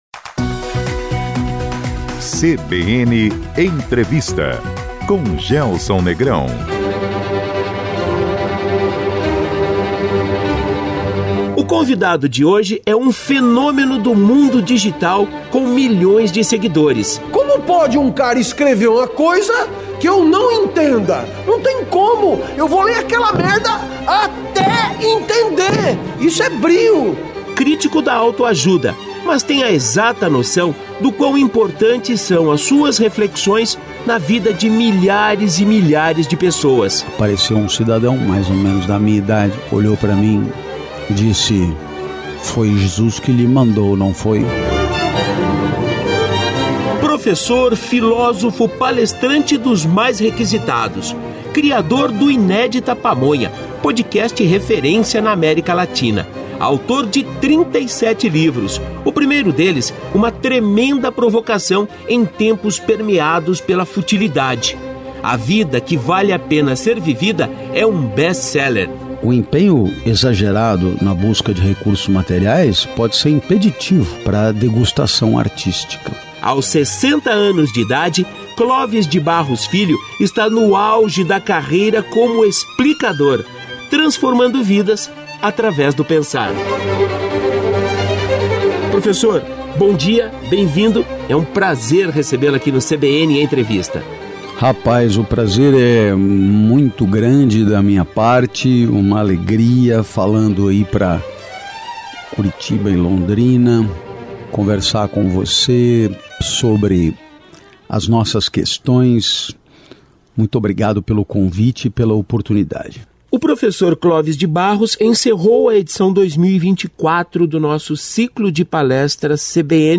Confira a primeira parte da entrevista com o professor Clóvis de Barros Filho: